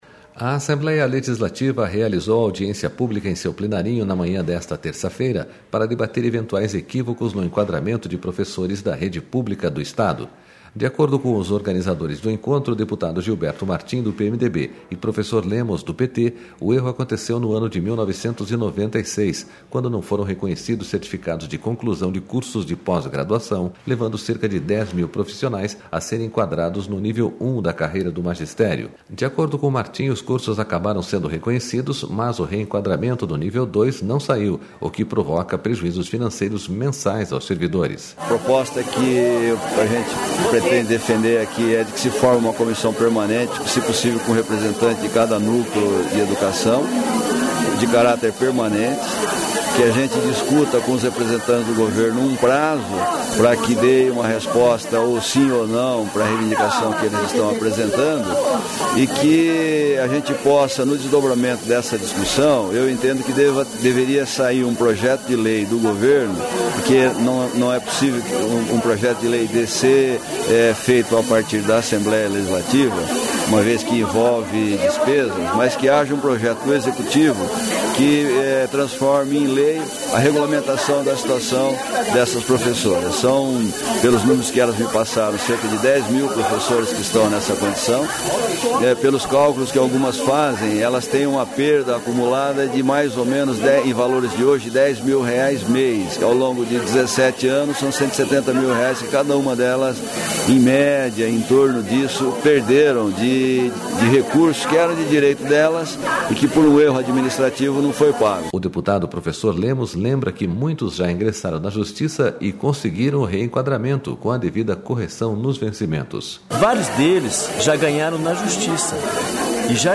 Audiência pública discute correção no reenquadramento de professores